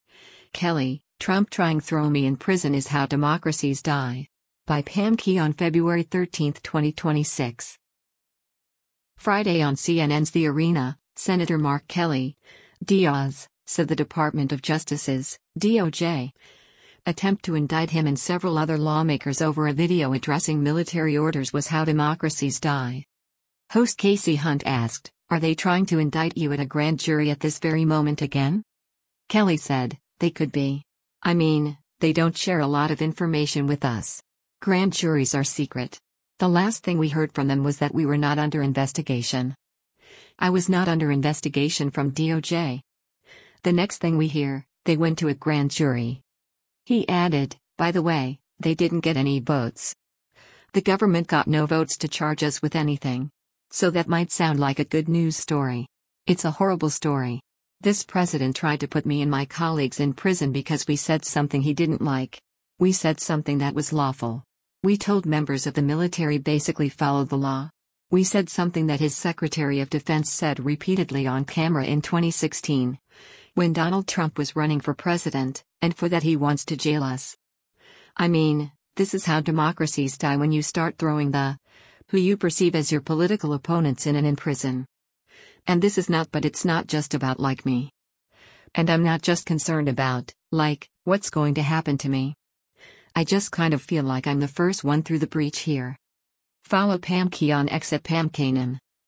Host Kasie Hunt asked, “Are they trying to indict you at a grand jury at this very moment again?”